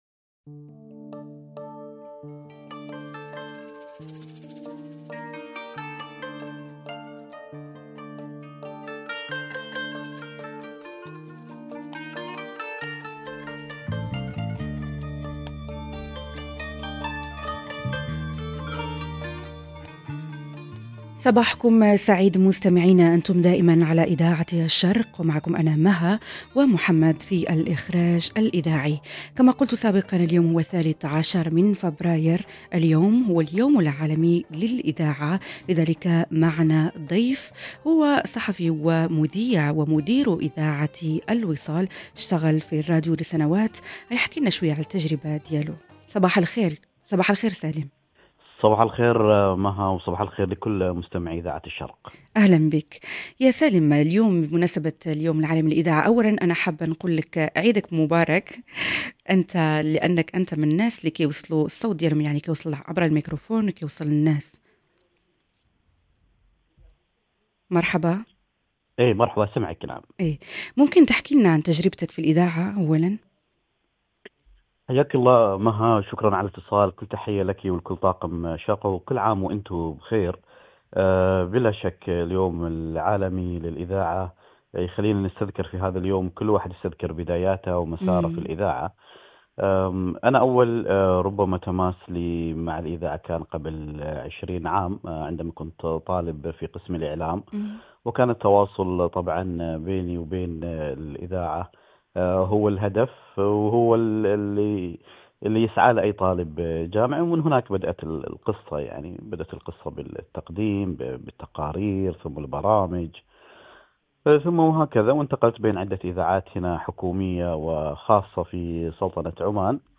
في برنامج سوا من باريس وبمناسبة اليوم العالمي للإذاعة، استضفنا الصحفي والمذيع،